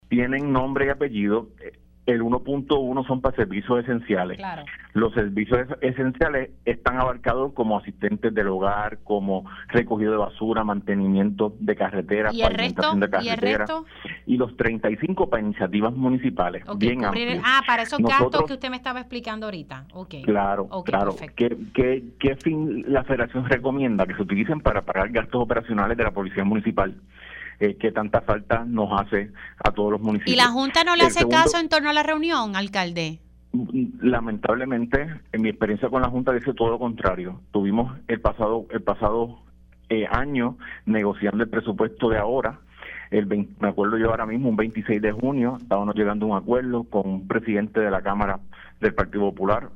120-GABRIEL-HERNANDEZ-ALC-CAMUY-Y-PRES-FED-ALCALDES-HAY-36-MILLONES-QUE-SE-PIERDEN-SI-NO-SE-DESEMBOLSAN.mp3